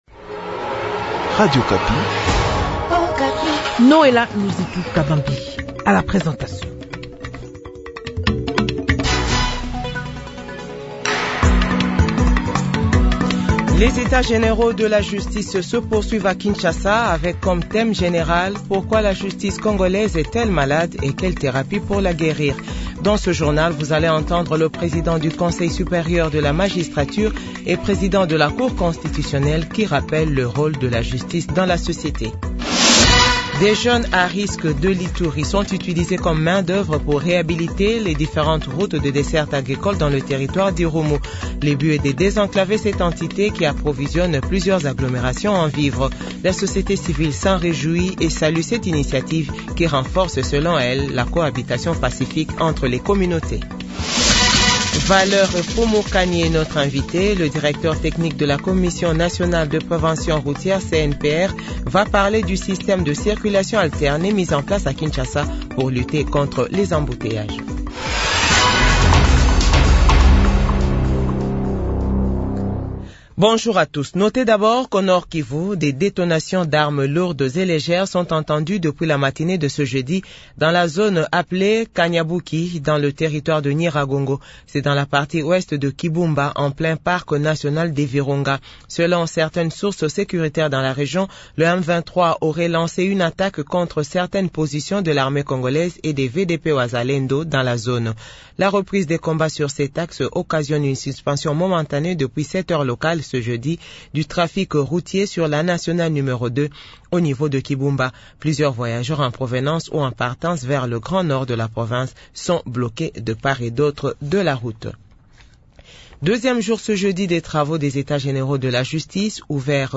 JOURNAL FRANÇAIS DE 15H00